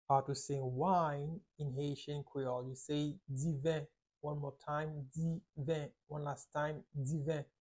"Wine" means "Diven" in Haitian Creole - "Diven" pronunciation by a native Haitian tutor
“Diven” Pronunciation in Haitian Creole by a native Haitian can be heard in the audio here or in the video below:
How-to-say-Wine-in-Haitian-Creole-Diven-pronunciation-by-a-native-Haitian-tutor.mp3